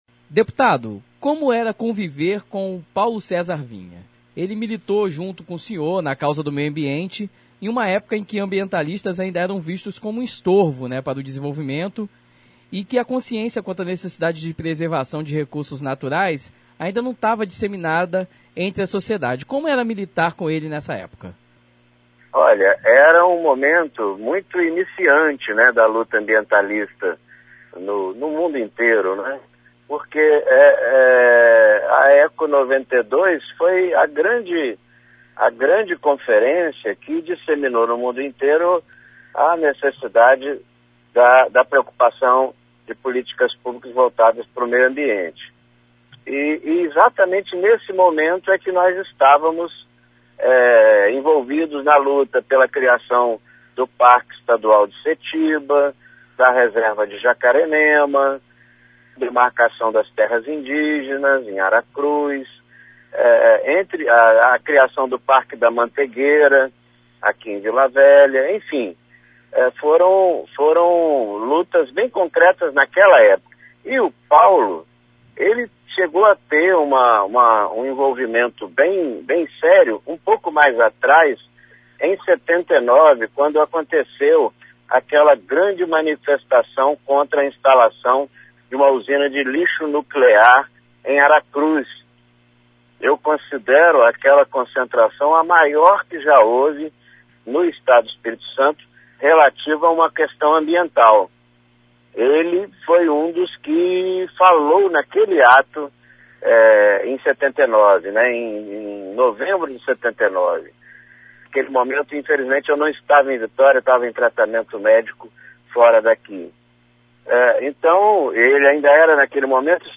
Confira a entrevista: Entrevista deputado estadual Claudio Vereza Download : Entrevista deputado estadual Claudio Vereza